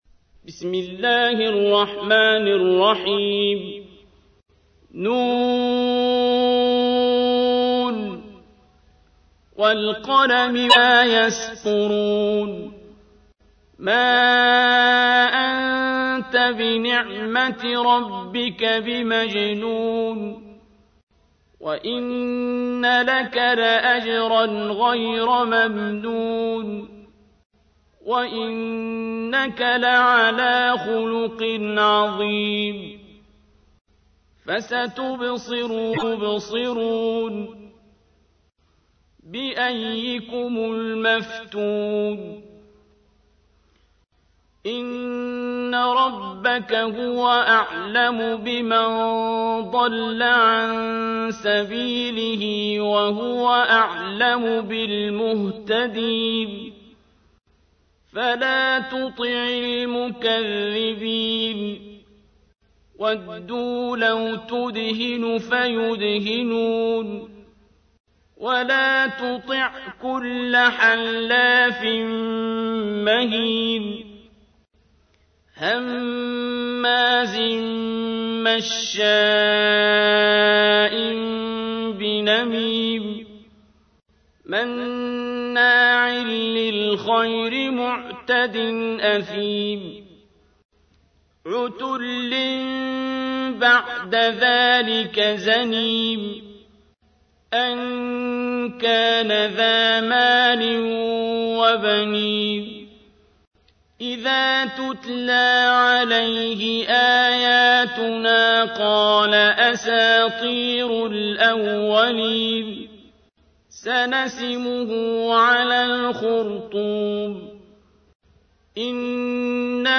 تحميل : 68. سورة القلم / القارئ عبد الباسط عبد الصمد / القرآن الكريم / موقع يا حسين